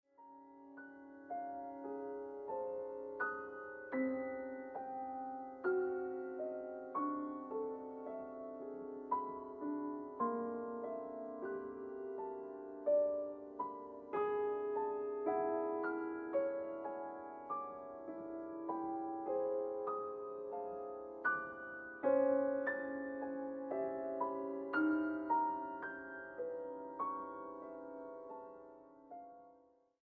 mezzosoprano
pianista.
Grabado en la Sala Nezahualcóyotl